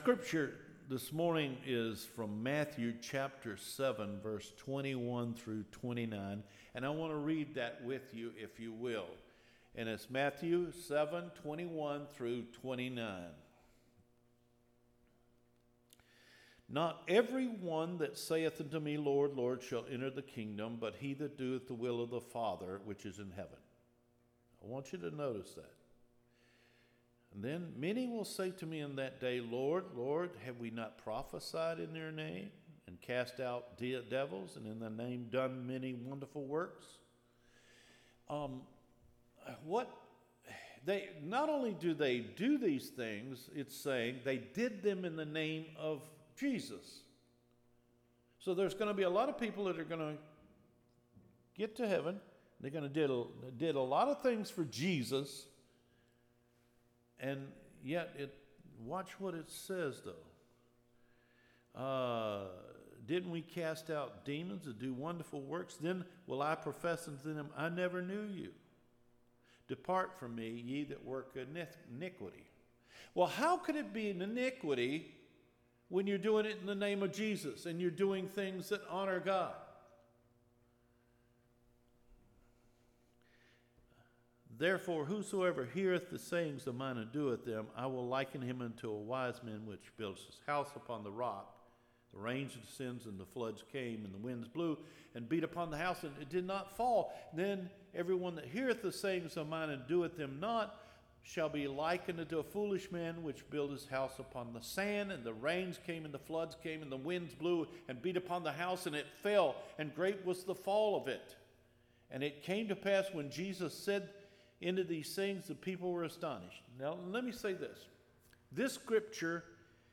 Recorded Sermons